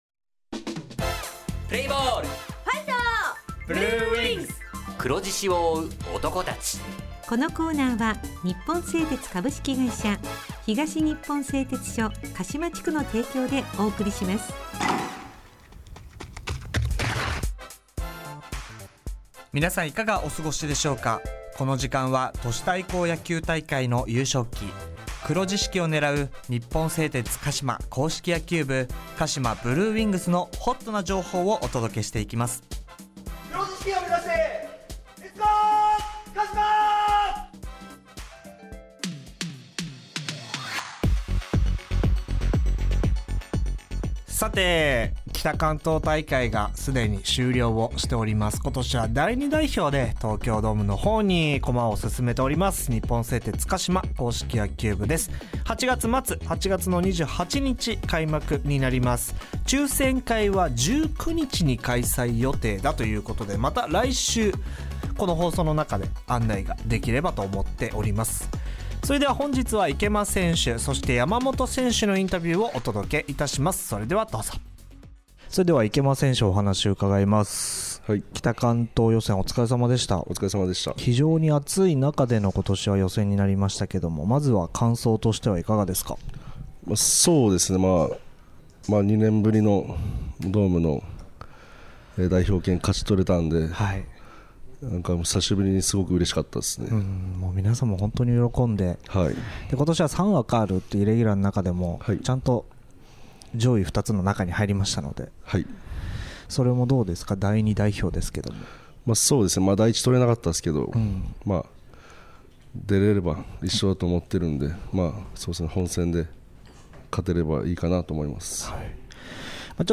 地元ＦＭ放送局「エフエムかしま」にて鹿島硬式野球部の番組放送しています。
《選手インタビュー》